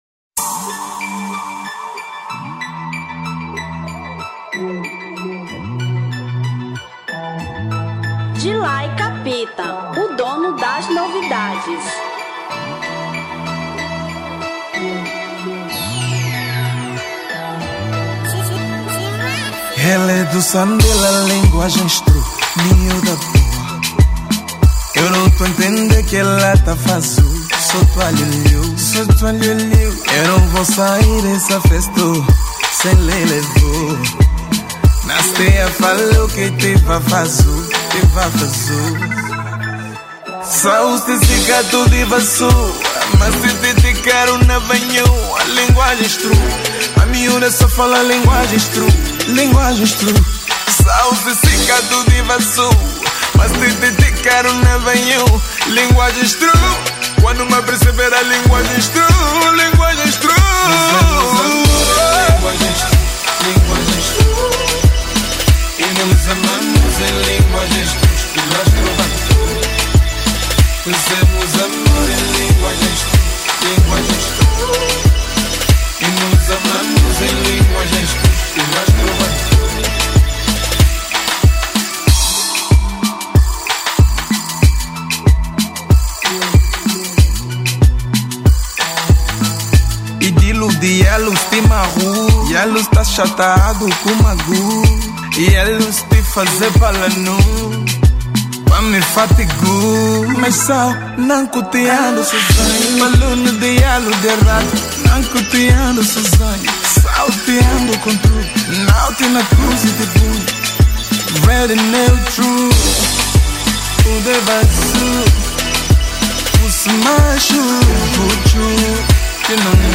Afro Pop 2017